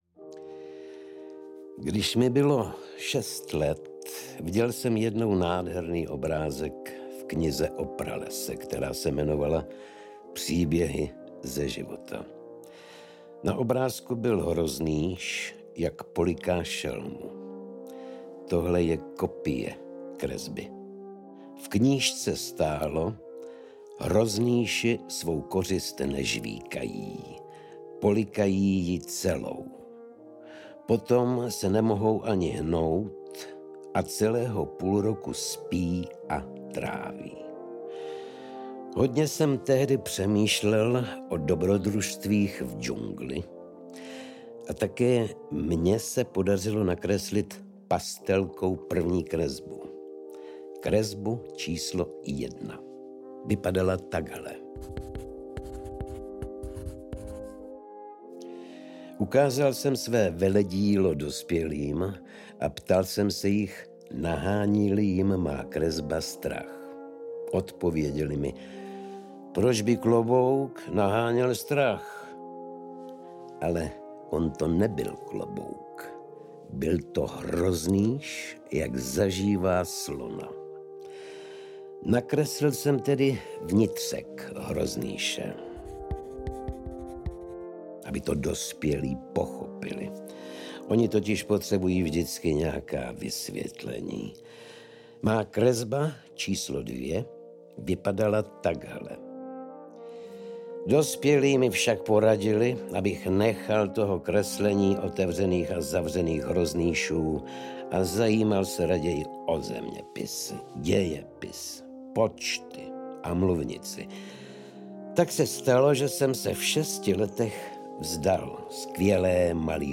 Malý princ audiokniha
Ukázka z knihy
Audiokniha je rovněž doplněná autorskou hudbou Zdeňka Berana.
• InterpretOldřich Kaiser, Lucie Vondráčková, Audiofairytellers